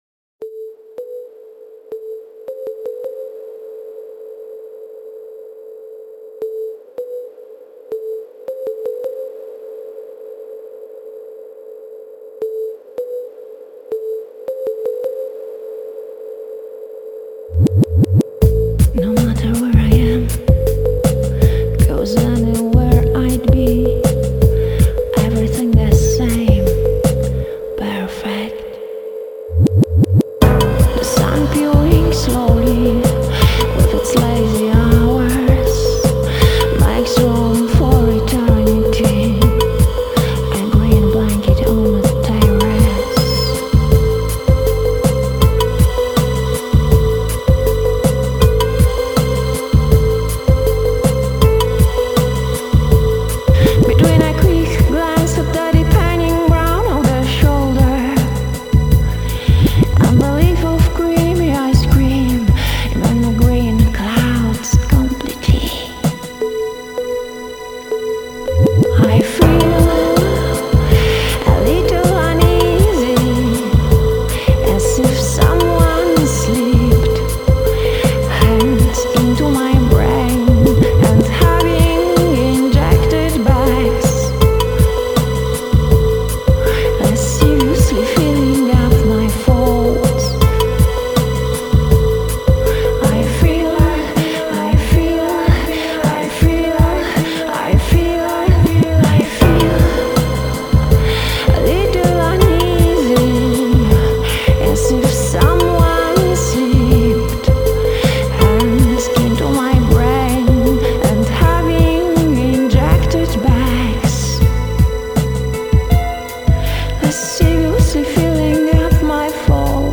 польский трип-хоп с единственным альбомом в 2004-м году!